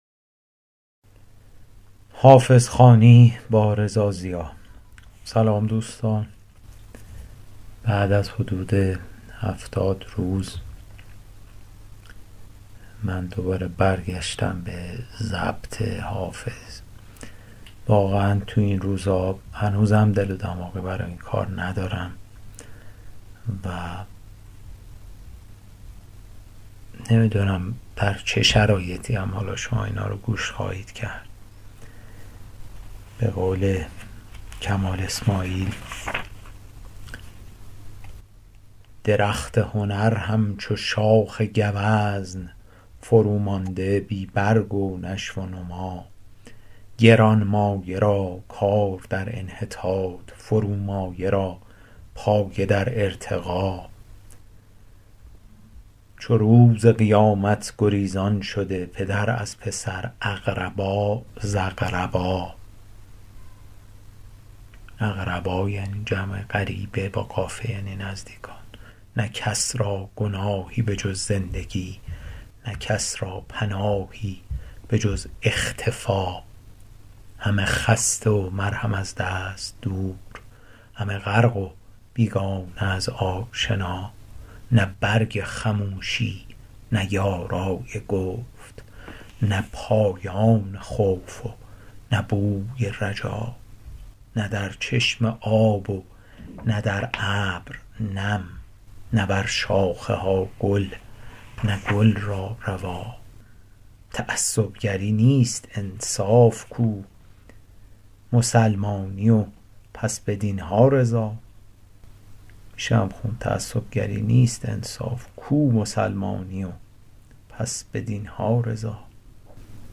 شرح صوتی